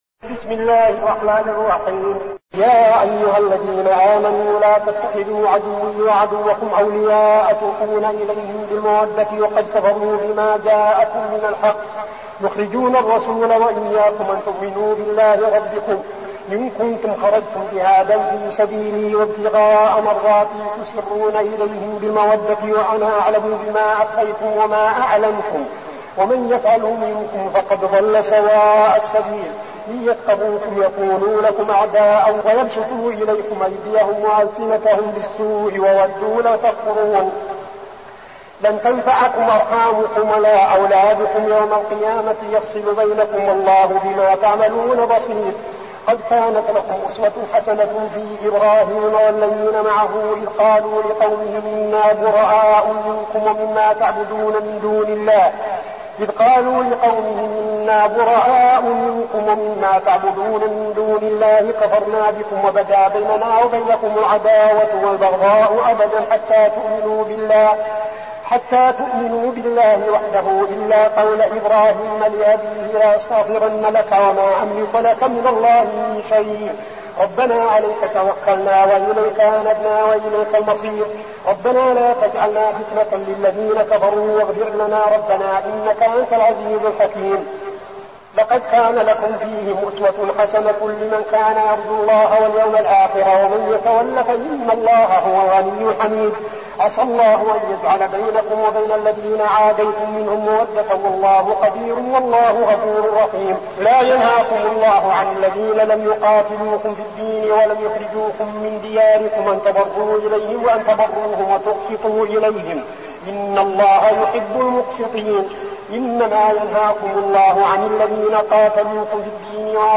المكان: المسجد النبوي الشيخ: فضيلة الشيخ عبدالعزيز بن صالح فضيلة الشيخ عبدالعزيز بن صالح الممتحنة